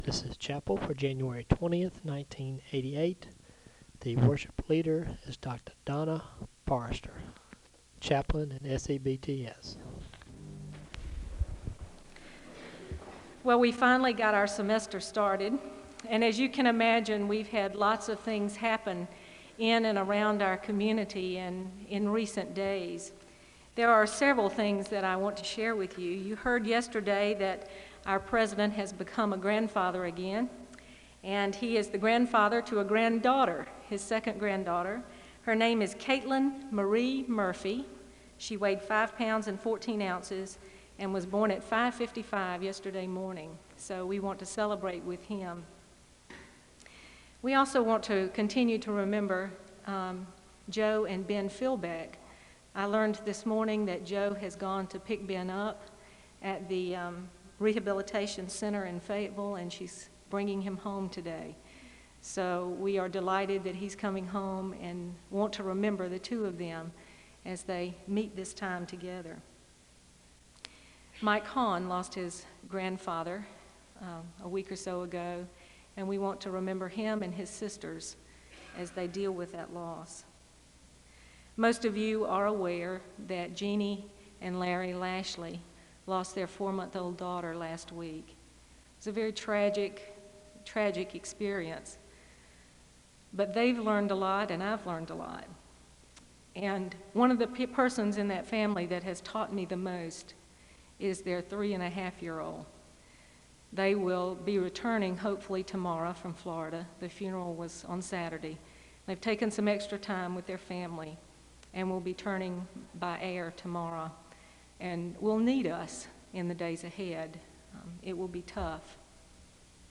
The service begins with the sharing of prayer concerns and a moment of prayer (0:00-4:54). There is a Scripture reading from John 14 (4:55-8:01).
There is a hymn of worship (24:00-27:27). There is a confessional litany (27:28-29:30).